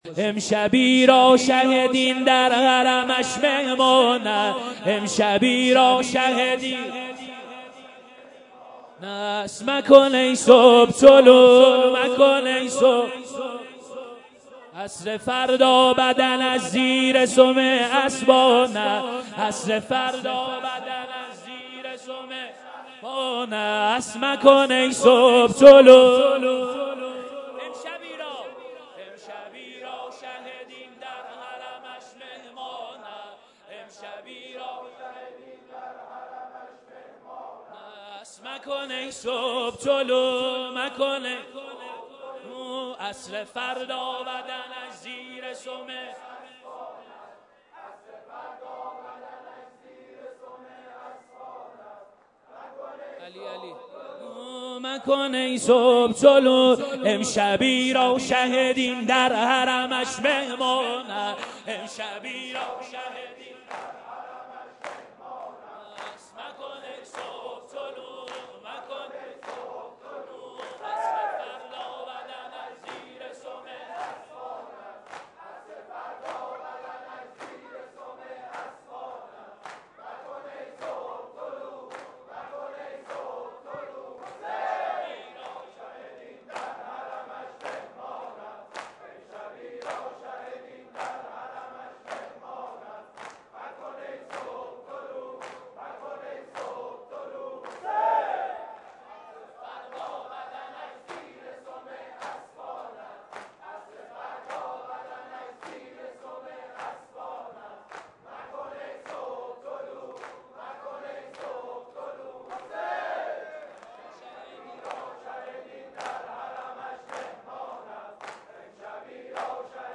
سینه زنی بخش چهارم